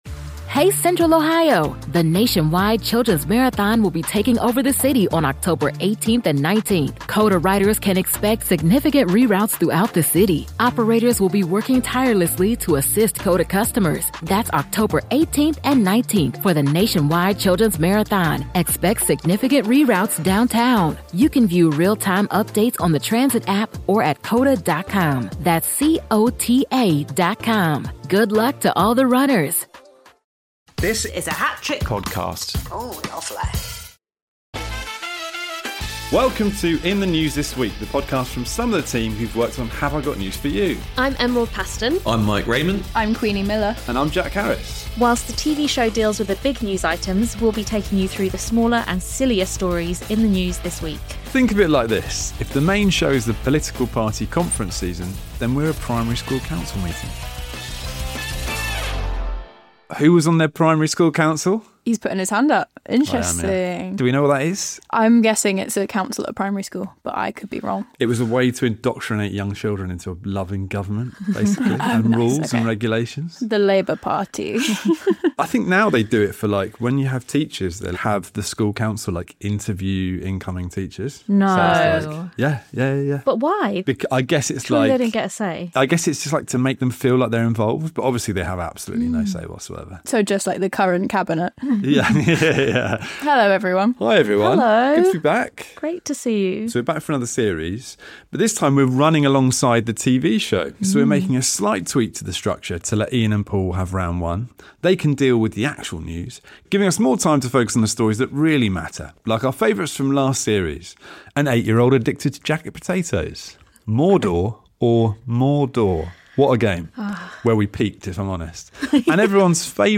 Later, they chat about the real root of a suspected gas leak in Lancashire, the name of the new train destined to defeat dangerous leaves on the line and what Labour members were given to survive their recent party conference…